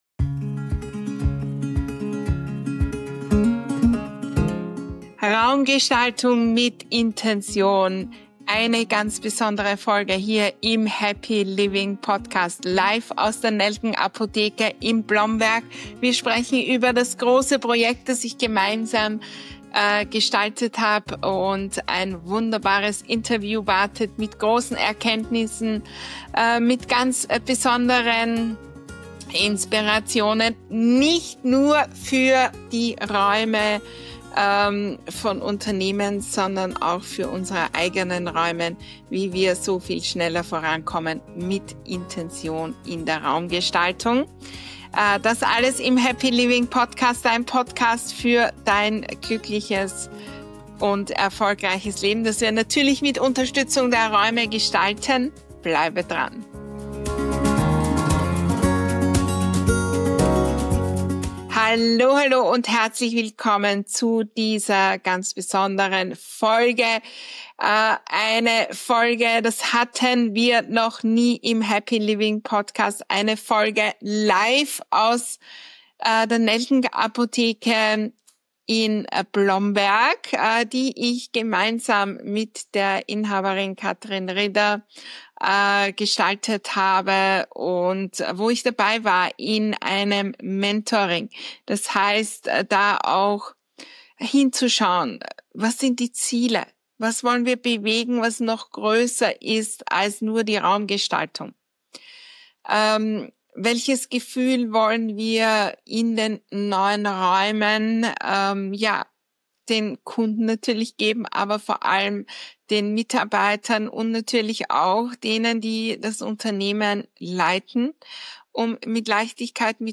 In dieser besonderen Folge des Happy Living Podcasts geht es um Raumgestaltung mit Intention – live aus der Nelken-Apotheke in Blomberg.